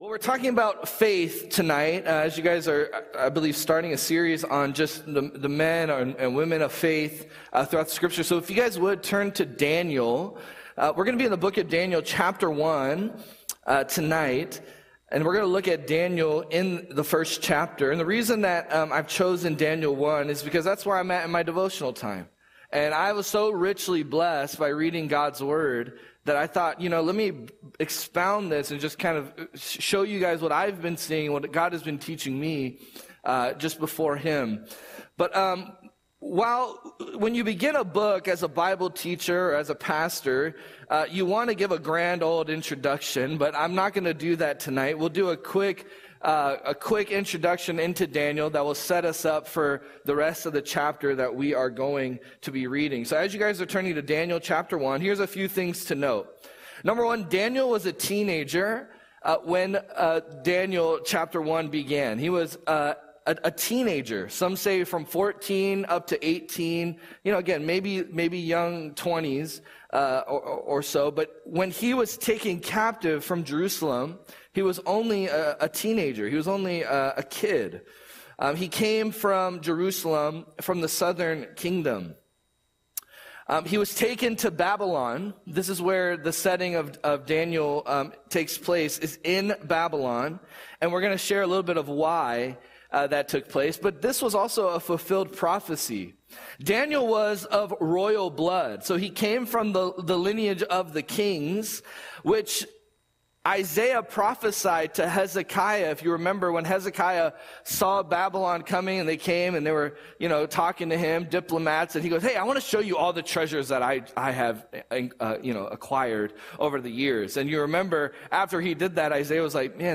Audio Sermon - March 19, 2025